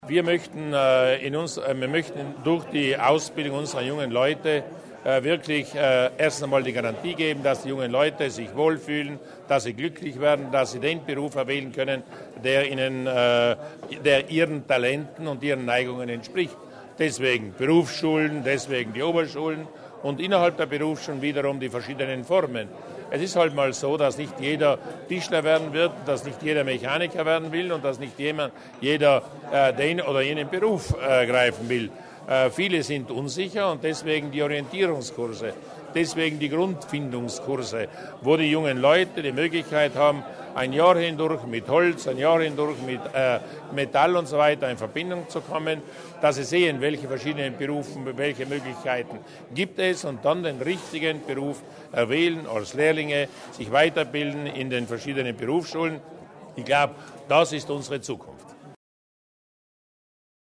Landeshauptmann Durnwalder zur Bedeutung der Berufsbildung